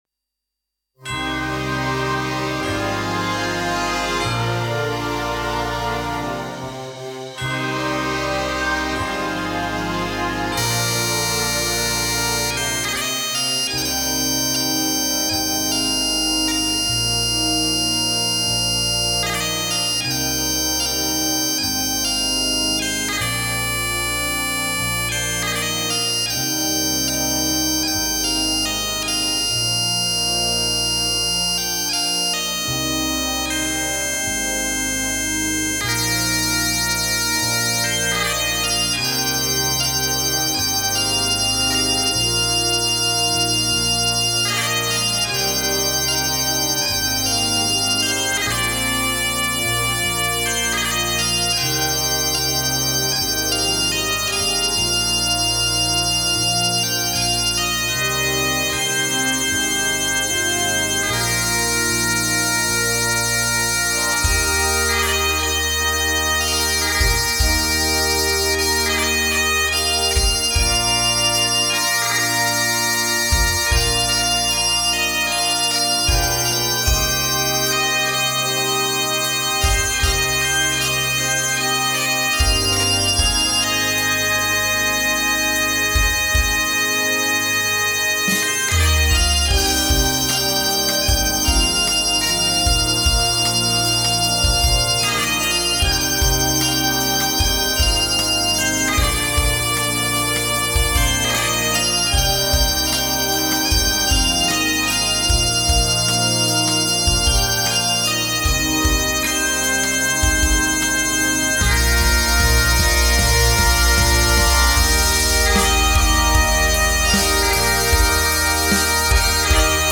CategoryConcert Band & Bagpipes
InstrumentationBagpipes
Timpani
Drum Set
Bass Guitar